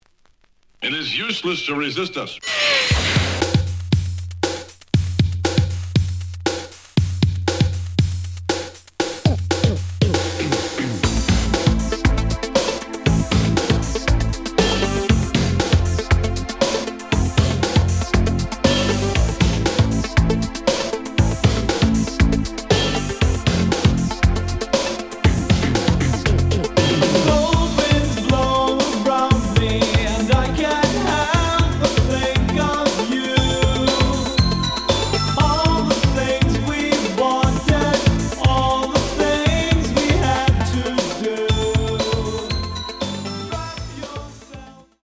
The audio sample is recorded from the actual item.
●Genre: Hip Hop / R&B